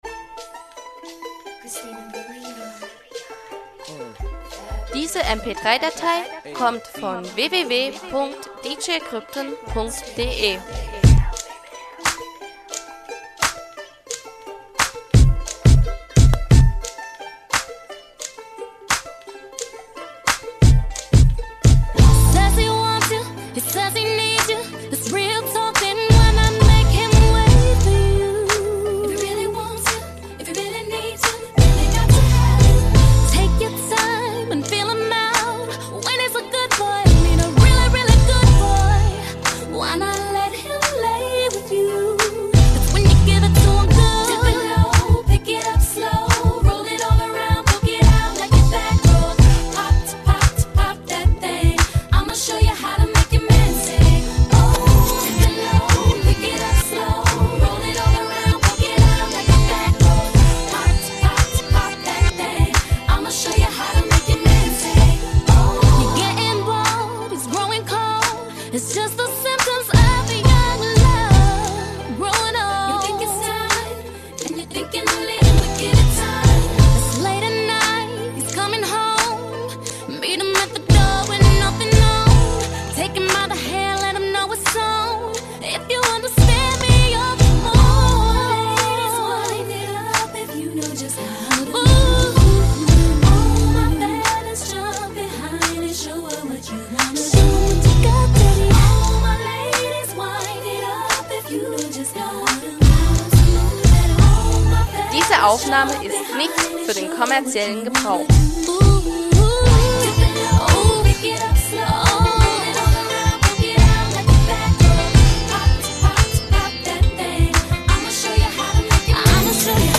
mixtape
R&B mix